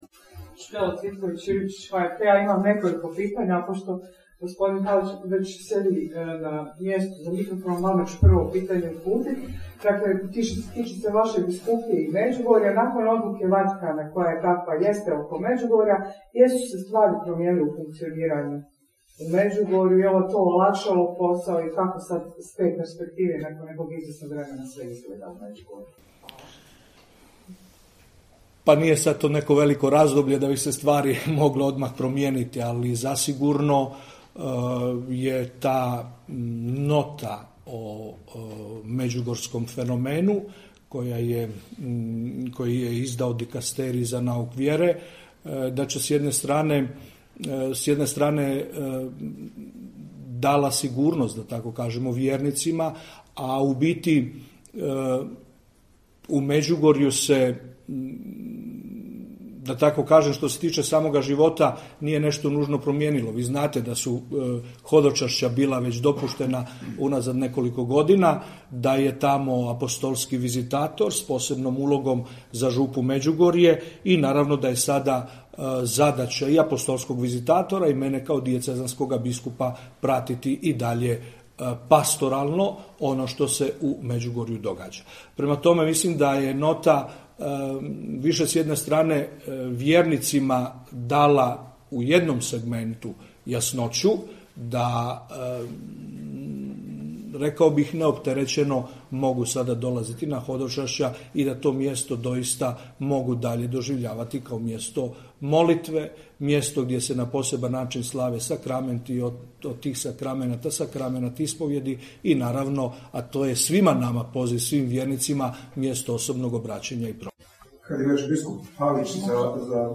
Video: Nadbiskup Vukšić i biskup Palić govorili na tiskovnoj konferenciji o 91. redovitom zasjedanju Biskupske konferencije BiH